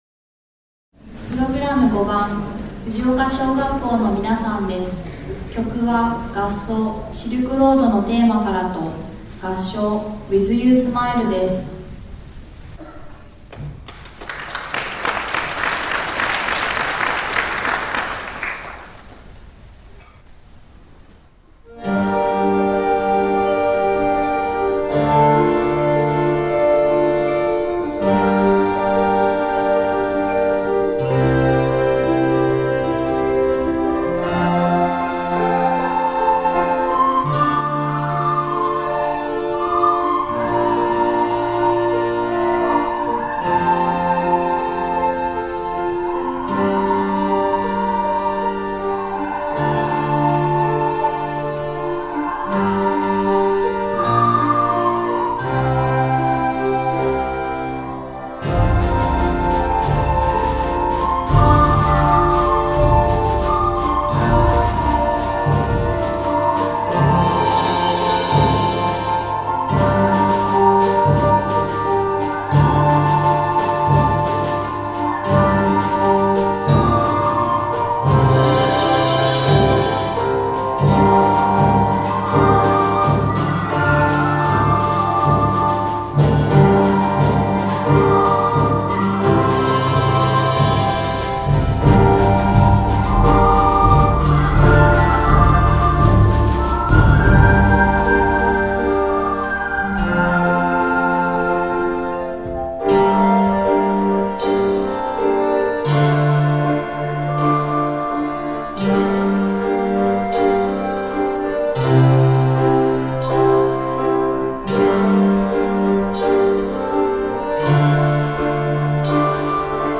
藤岡文化会館で「わたらせ風の子音楽祭２０１３」が開催され、６年生が合唱と合奏を発表しました。
発表した曲は、合奏「シルクロードのテーマから」、合唱「With You Smile」です。